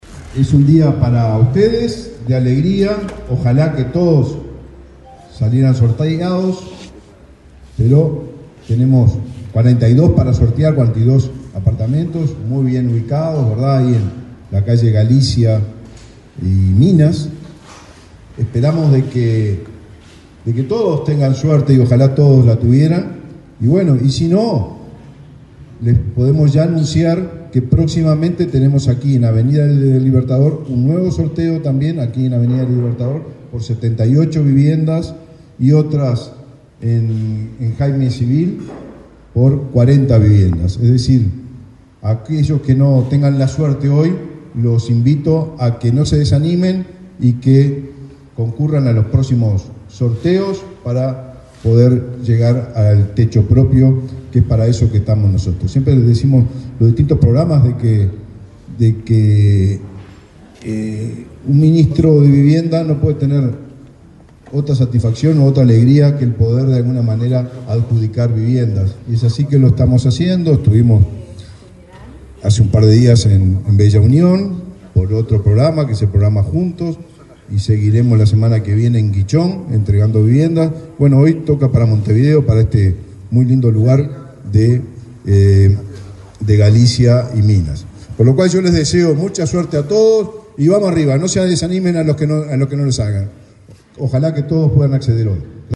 Palabras del ministro de Vivienda, Raúl Lozano
Palabras del ministro de Vivienda, Raúl Lozano 09/08/2024 Compartir Facebook X Copiar enlace WhatsApp LinkedIn Este viernes 9, el ministro de Vivienda, Raúl Lozano, participó en el sorteo de 41 viviendas de 1, 2 o 3 dormitorios en un edificio sito en Minas y Galicia, Montevideo. En la oportunidad, se aplicaron las modalidades de compra y alquiler con opción a compra.